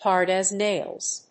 アクセント(as) hárd [tóugh] as náils 《口語》